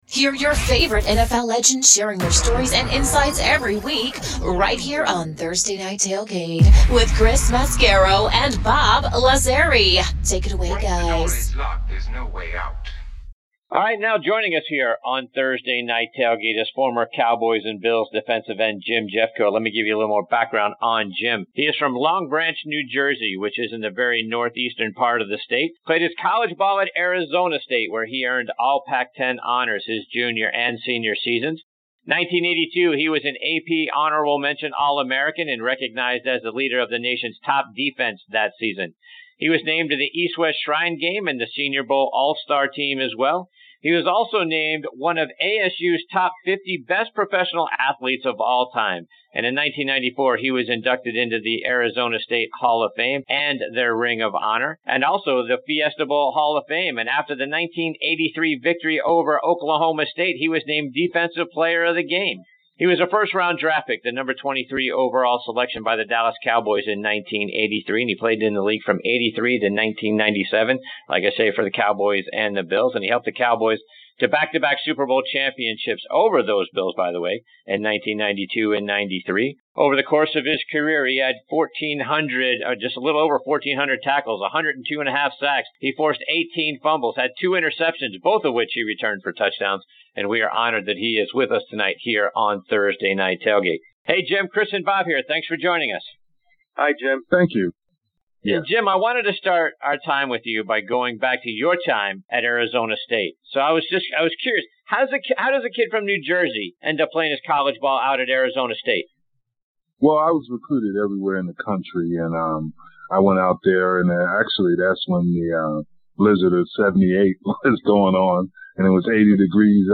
Jim Jeffcoat, former Cowboys 2 Time Super Bowl Champion DE, Joins Us on this Segment of Thursday Night Tailgate NFL Podcast